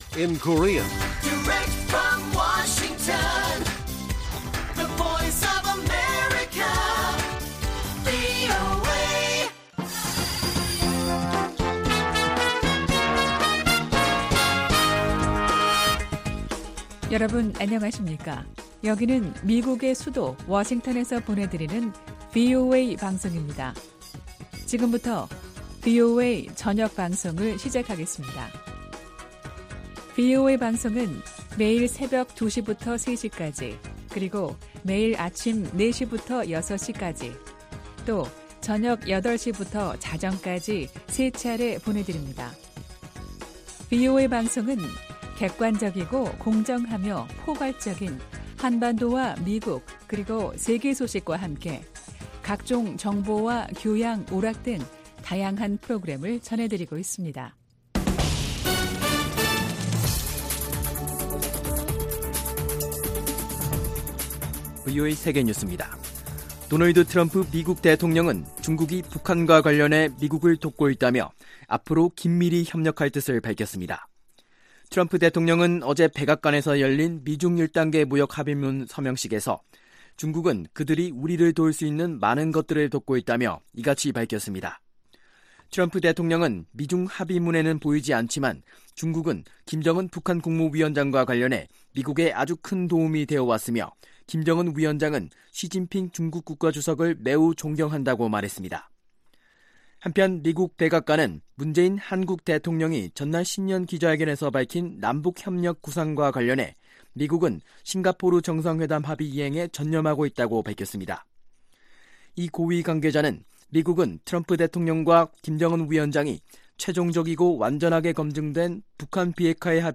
VOA 한국어 간판 뉴스 프로그램 '뉴스 투데이', 2019년 1월 16일 1부 방송입니다. 미국 백악관은 남북 간 협력사업을 적극 추진한다는 한국 정부의 방침과 관련해, 미국은 싱가포르 미-북 정상회담 합의 이행에 전념하고 있다고 강조했습니다. 한국 정부는 이산가족 상봉 등의 방식을 통한 북한 개별 관광을 본격적으로 추진한다는 방침입니다.